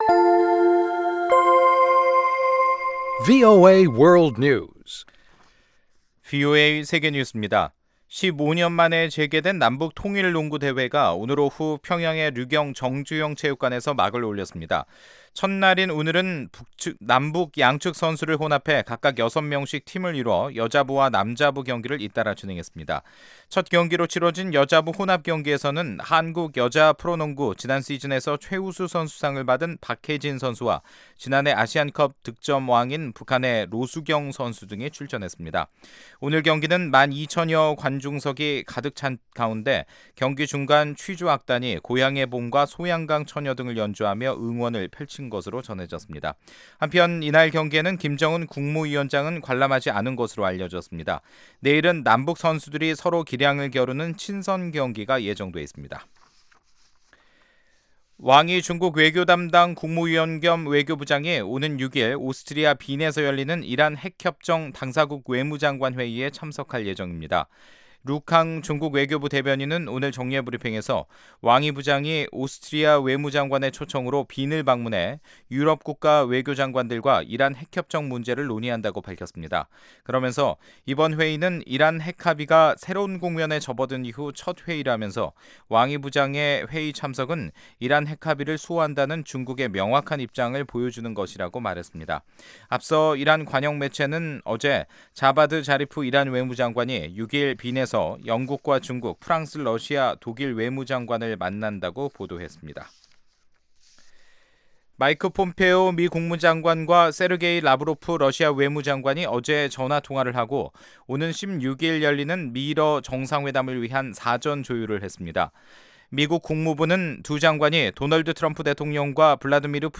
세계 뉴스와 함께 미국의 모든 것을 소개하는 '생방송 여기는 워싱턴입니다', 2018년 7월 4일 저녁 방송입니다. ‘지구촌 오늘’ 에서는미국 정부가 중국 유력 통신장비회사 ZTE(중싱퉁쉰)에 대한 제재를 일부 풀었다는 소식, ‘아메리카 나우’에서는 트럼프 행정부가 대학에 인종 다양성을 장려해온 이전 오바마 정부 당시 지침을 철회했다는 이야기 전해드립니다. '타박타박 미국 여행'에서는 마이클 잭슨의 고향, 인디애나주를 찾아갑니다.